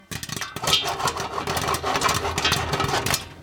ding field-recording metal-pan pop popcorn stove sound effect free sound royalty free Sound Effects